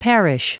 Prononciation du mot parish en anglais (fichier audio)
Prononciation du mot : parish